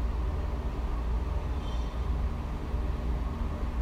We did, however, capture an interesting EVP down in the women’s bathroom.
johnny-mango-womens-room-noise.wav